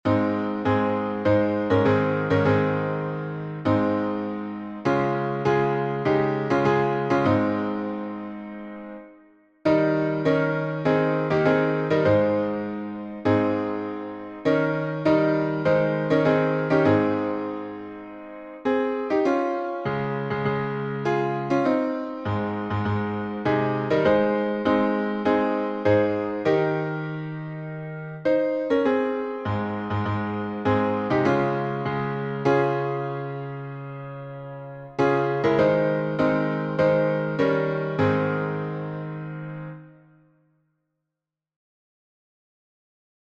Meter: 8.7.8.7. with Refrain